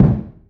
Solid Kickdrum C Key 715.wav
Royality free bass drum tuned to the C note. Loudest frequency: 192Hz
solid-kickdrum-c-key-715-VrT.ogg